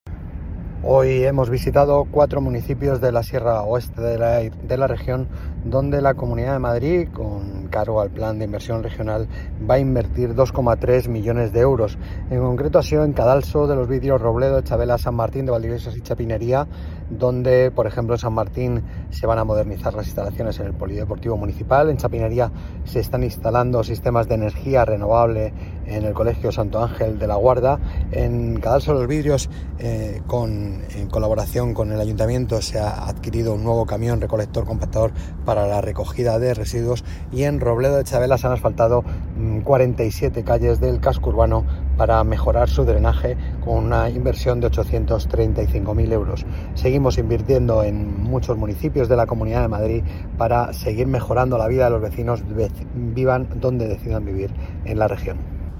SONIDO: El viceconsejero de Presidencia y Administración Local, José Antonio Sánchez.